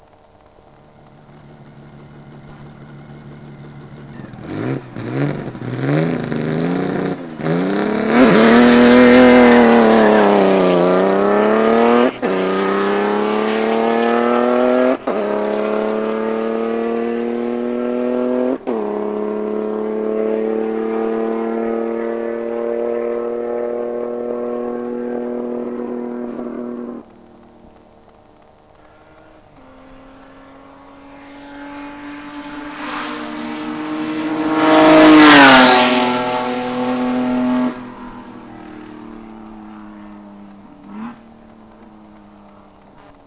- Hubraum / Bauart des Motors : 3442 ccm / 6 Zylinder in Reihe
engine1-dtype-1.wav